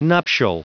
Prononciation du mot nuptial en anglais (fichier audio)
nuptial.wav